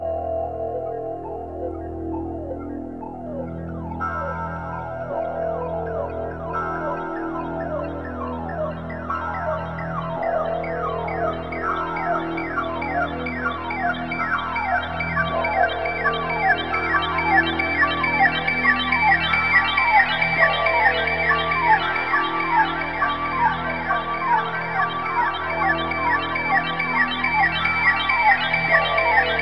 SPACY.wav